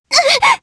Selene-Vox_Damage_jp_02.wav